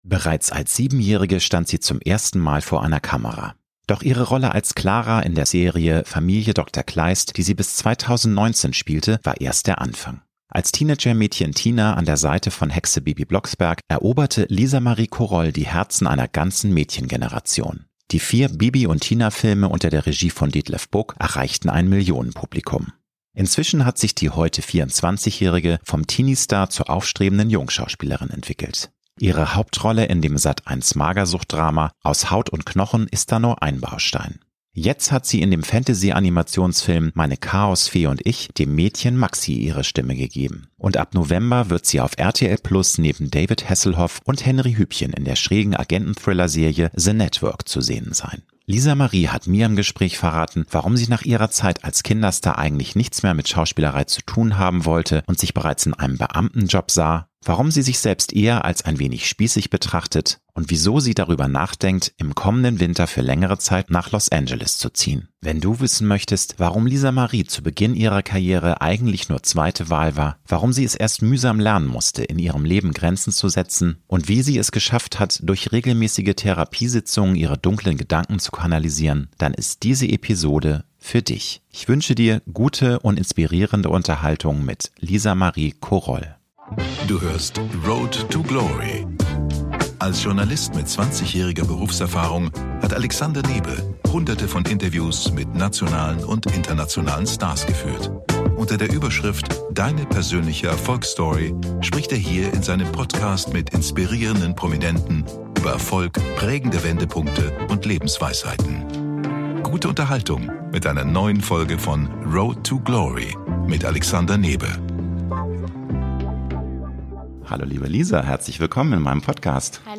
Lisa-Marie hat mir im Gespräch verraten, warum sie nach ihrer Zeit als Kinderstar eigentlich nichts mehr mit Schauspielerei zu tun haben wollte und sich bereits in einem Beamtenjob sah, warum sie sich selbst eher als ein wenig spießig betrachtet und wieso sie darüber nachdenkt, im kommenden Winter für längere Zeit nach Los Angeles zu gehen. Wenn du wissen möchtest, warum Lisa-Marie zu Beginn ihrer Karriere eigentlich nur zweite Wahl war, warum sie es erst mühsam lernen musste, in ihrem Leben Grenzen zu setzen und wie sie es geschafft hat, durch regelmäßige Therapiesitzungen ihre dunklen Gedanken zu kanalisieren, dann ist diese Episode für dich.